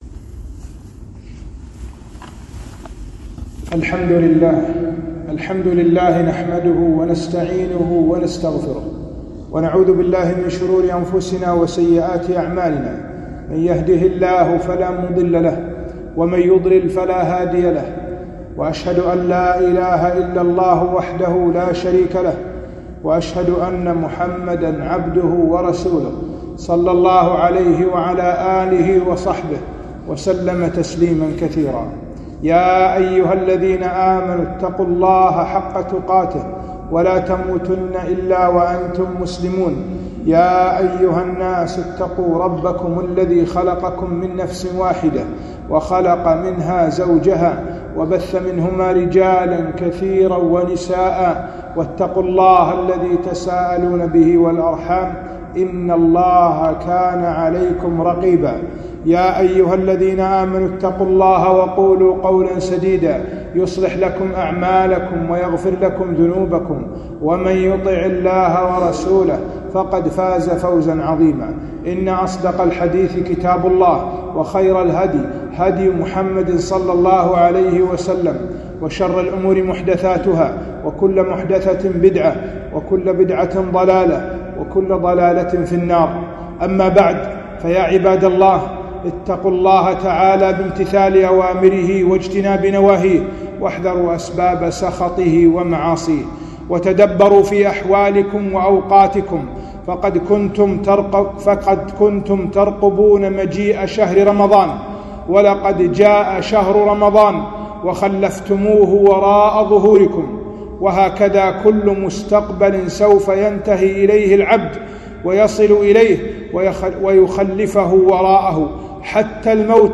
خطبة - الثبات على الطاعة بعد رمضان